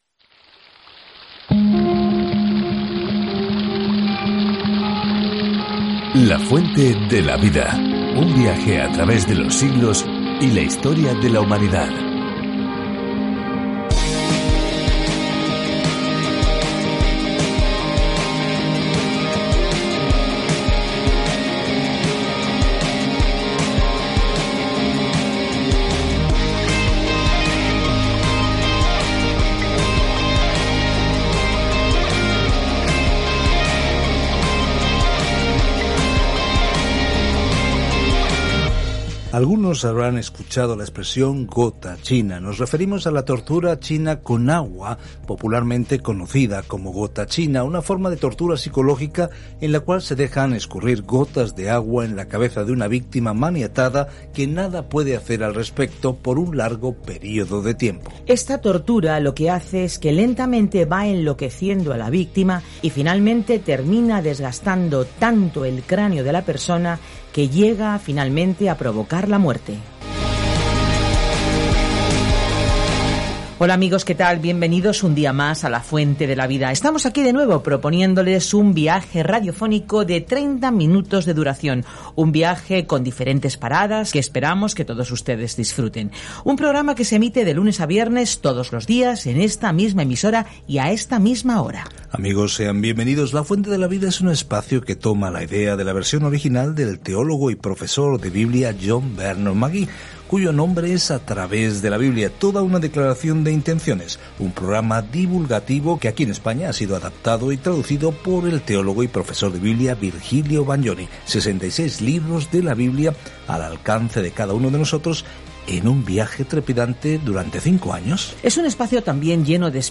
Escritura DANIEL 1:2-13 Día 1 Iniciar plan Día 3 Acerca de este Plan El libro de Daniel es a la vez una biografía de un hombre que creyó en Dios y una visión profética de quién eventualmente gobernará el mundo. Viaja diariamente a través de Daniel mientras escuchas el estudio de audio y lees versículos seleccionados de la palabra de Dios.